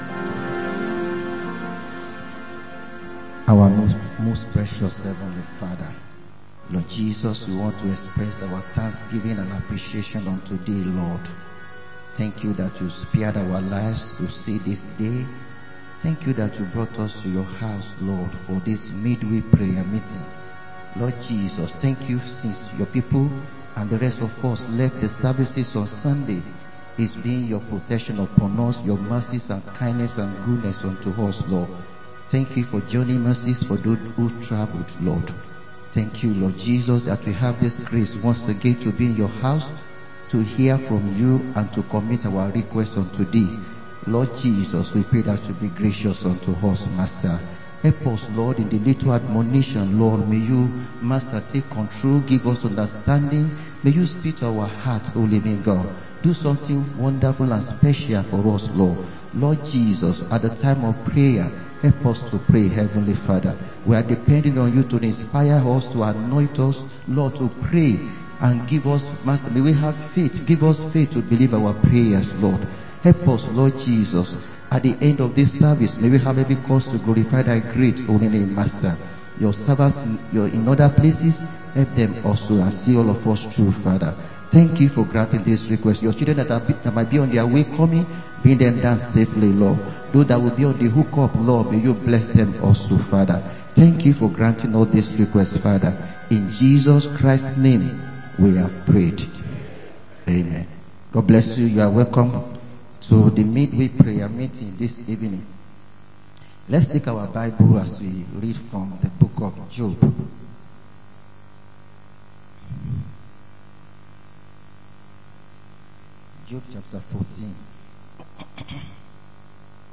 Wed. Prayer Meeting 01-10-25_Evang.